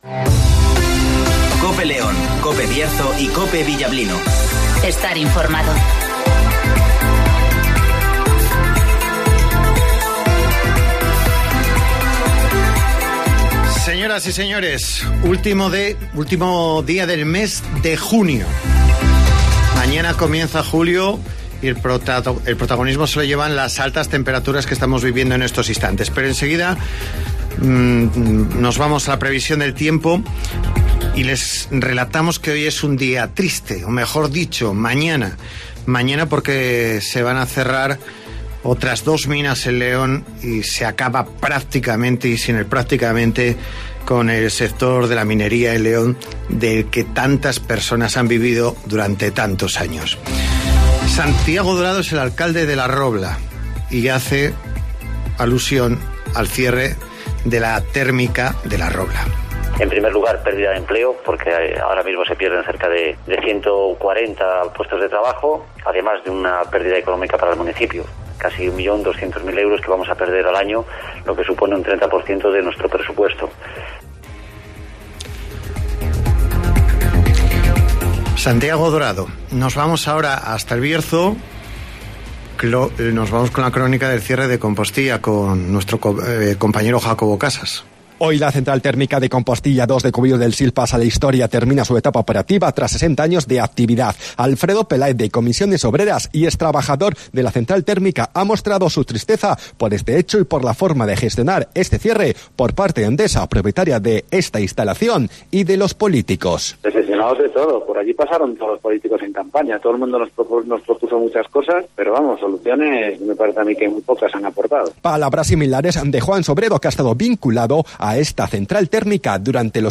Conocemos las noticias de las últimas horas del Bierzo y León, con las voces de los protagonistas.